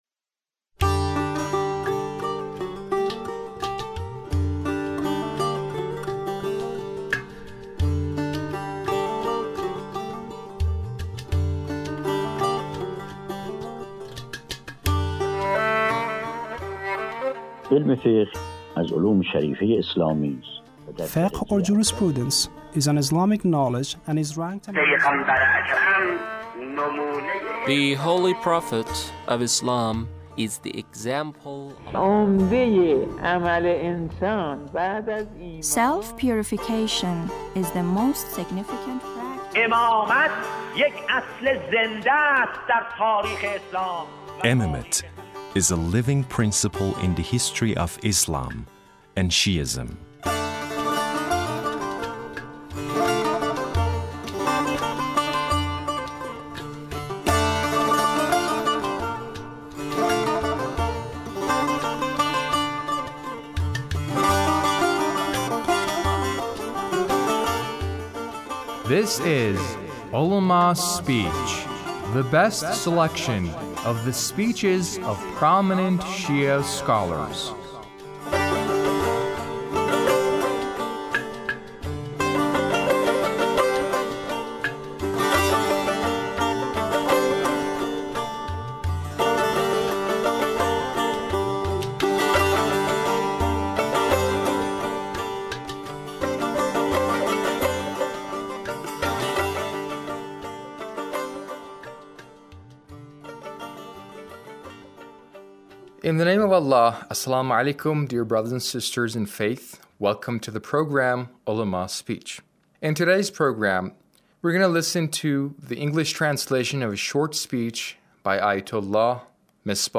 A radio documentary on the life of Shahid Qasem Suleimani - Part 4